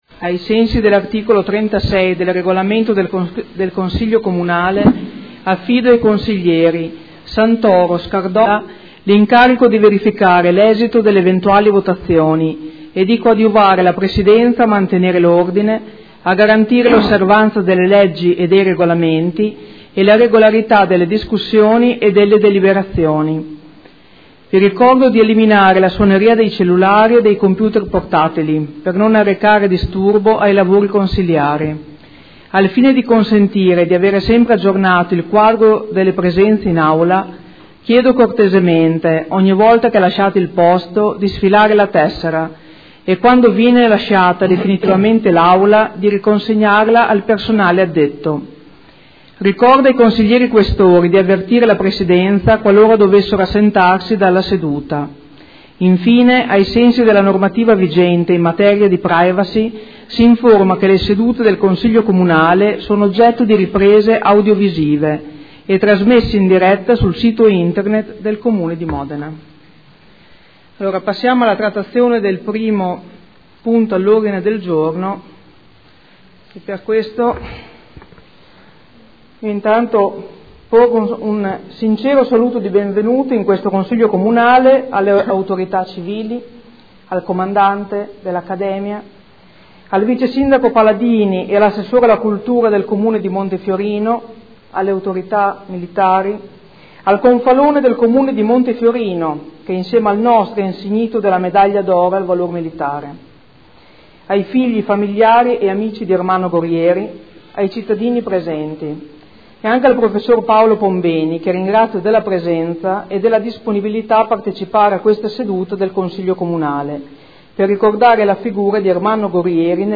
Seduta del 01/12/2014. Ricordo di Ermanno Gorrieri nel 10° anniversario dalla scomparsa, intervento della Presidente del Consiglio